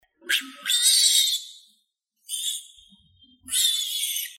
TengTongShiJiaoSheng.mp3